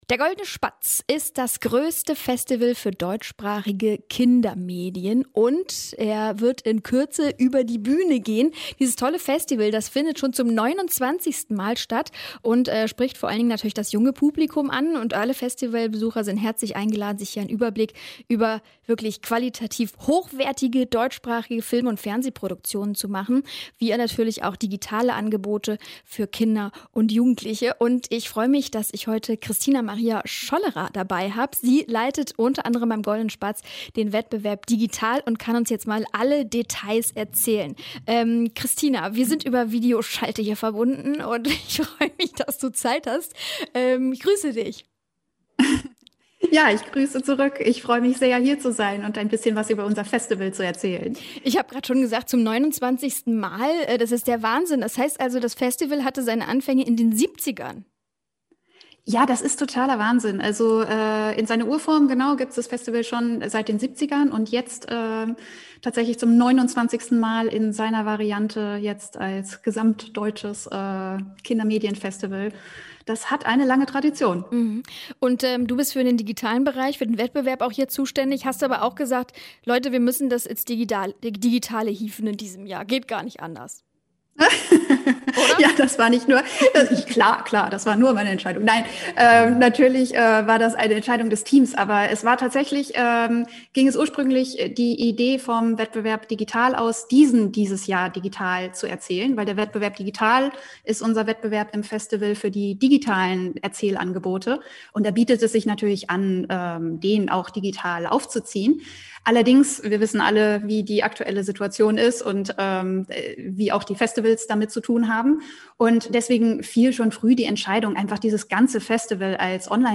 Interview mit FluxFM